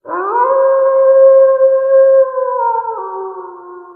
Serigala_Suara.ogg